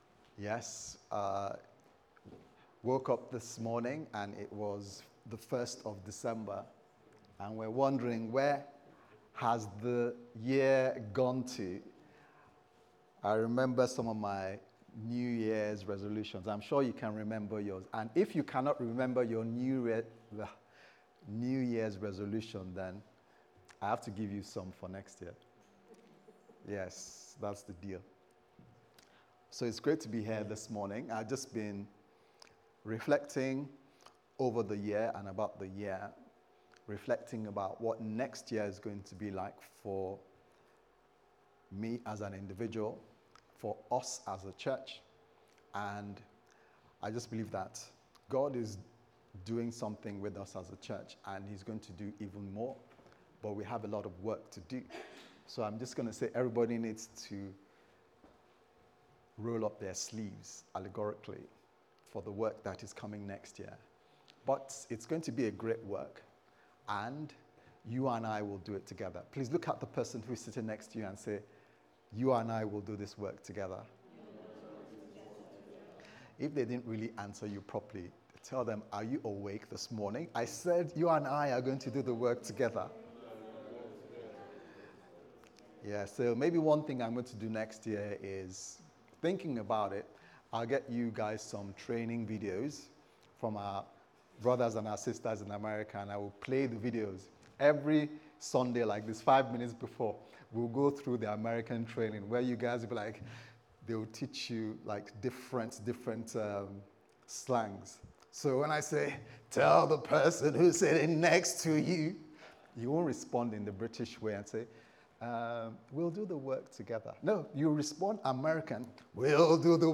Jesus Asked Who Do You Say I Am Service Type: Sunday Service Sermon « What Next